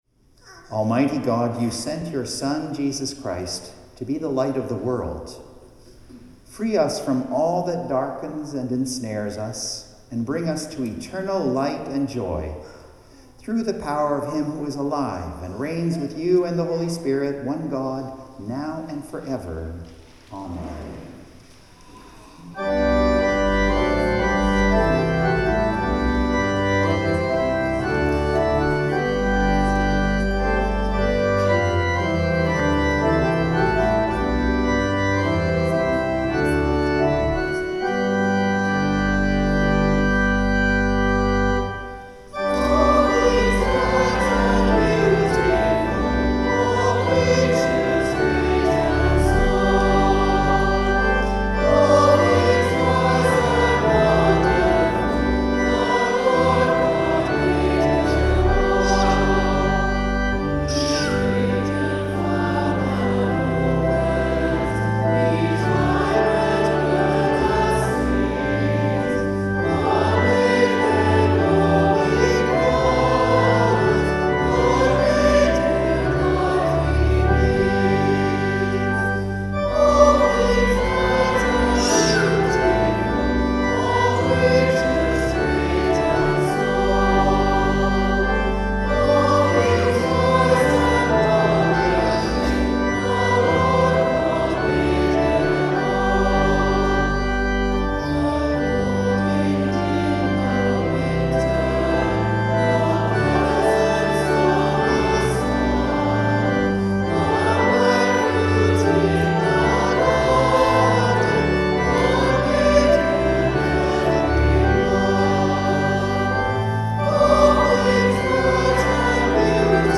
Sermons | St John the Evangelist